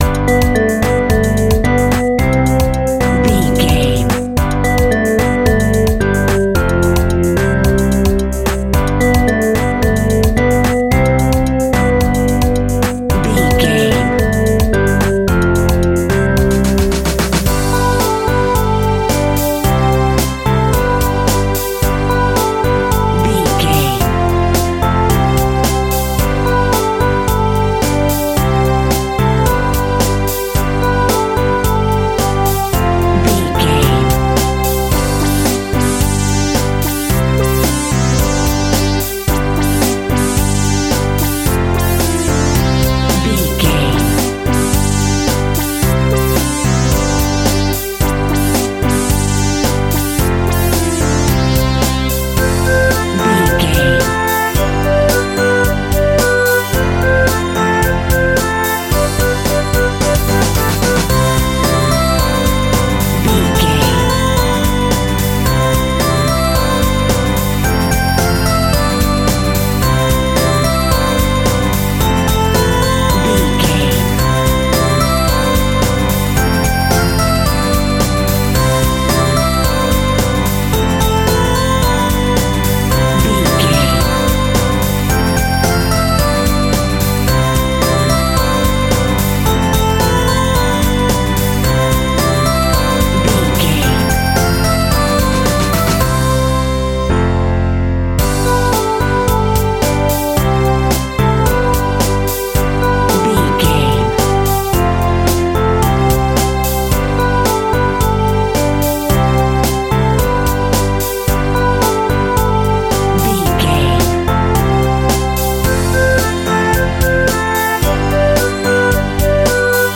Uplifting
Mixolydian
childrens music
instrumentals
fun
childlike
cute
happy
kids piano